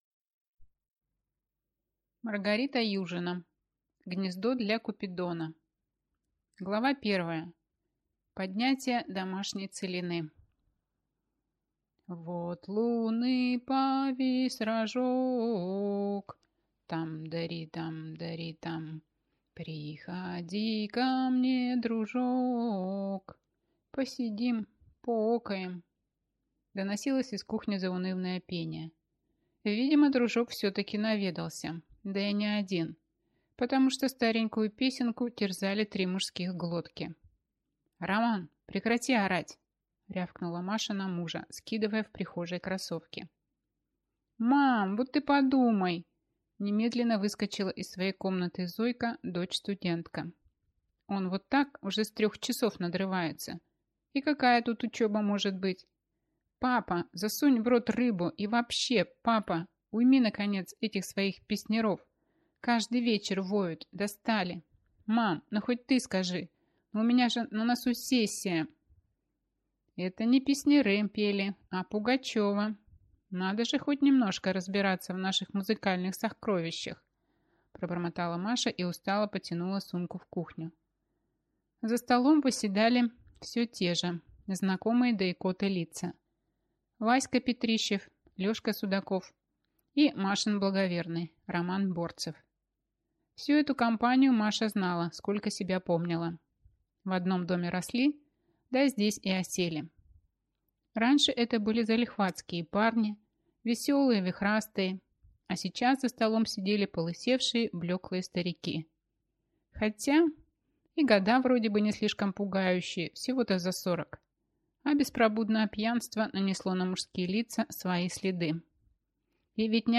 Аудиокнига Гнездо для купидона | Библиотека аудиокниг